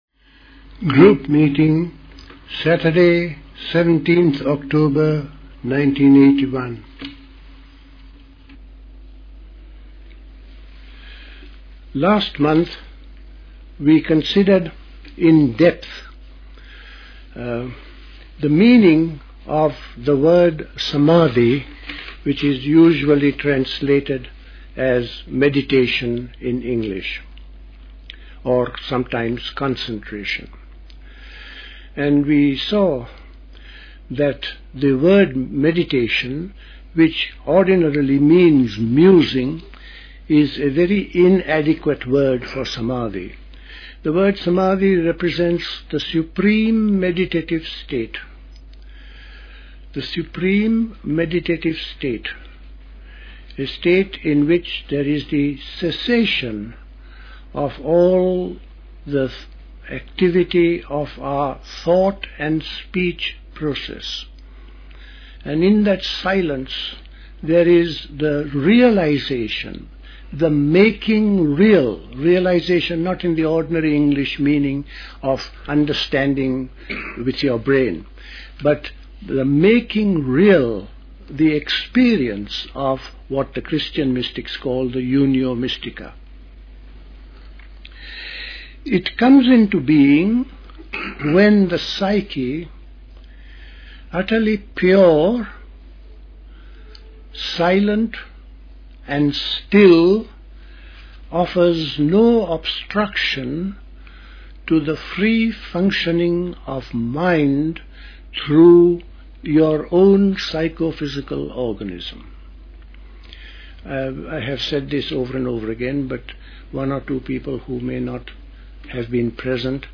at Dilkusha, Forest Hill, London on 17th October 1981